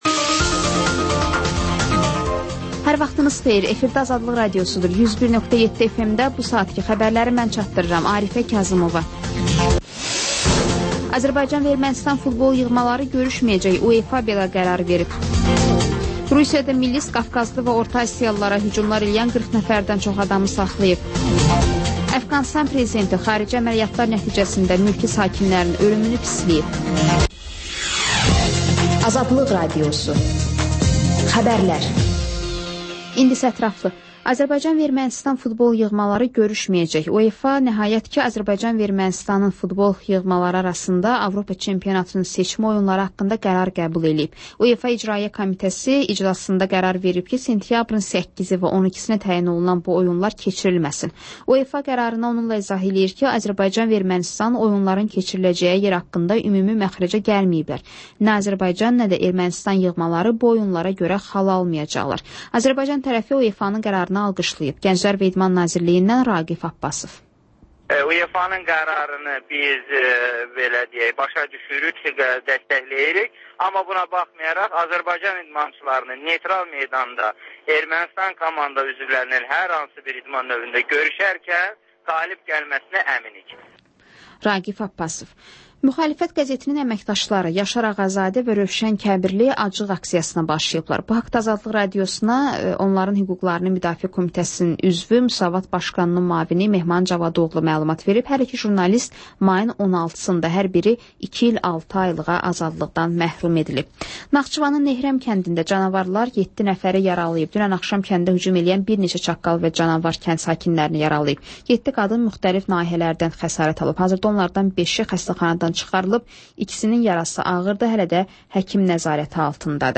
Xəbərlər, ardınca PANORAMA rubrikası: Həftənin aktual mövzusunun müzakirəsi